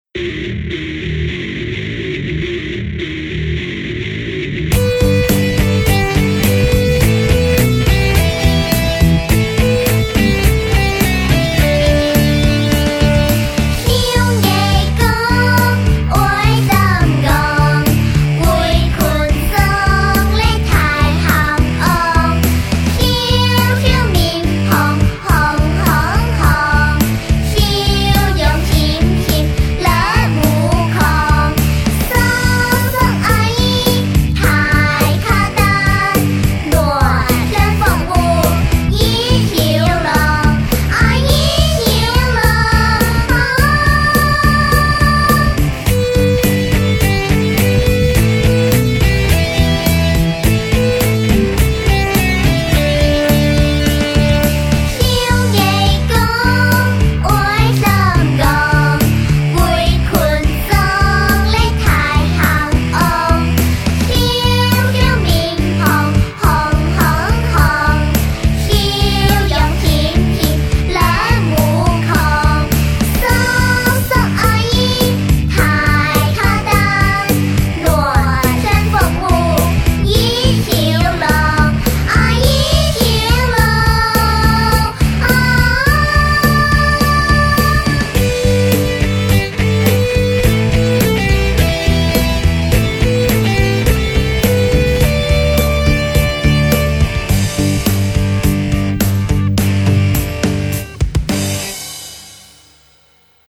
开平民歌集